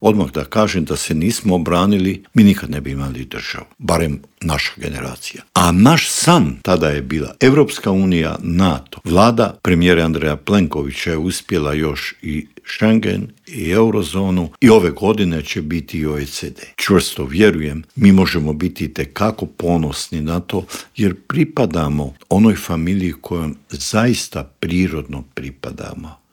ZAGREB - Uoči 34. godišnjice međunarodnog priznanja Hrvatske i 28. obljetnice završetka mirne reintegracije hrvatskog Podunavlja u Intervju Media servisa ugostili smo bivšeg ministra vanjskih poslova Matu Granića, koji nam je opisao kako su izgledali pregovori i što je sve prethodilo tom 15. siječnju 1992. godine.